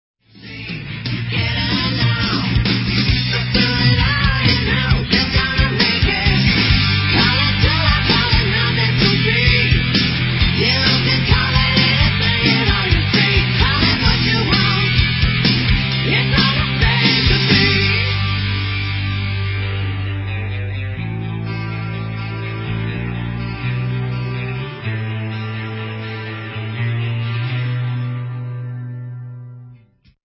GUITAR
DRUMS
VOCALS
BASS